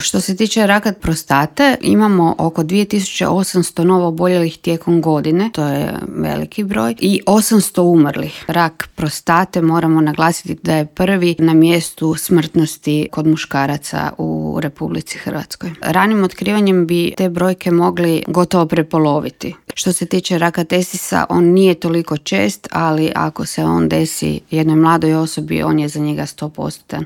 u novom intervjuu Media servisa povodom Movembera - mjeseca podizanja svijesti o zdravstvenim problemima muškaraca.